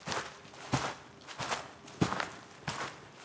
SFX_Footsteps_01_Reverb.wav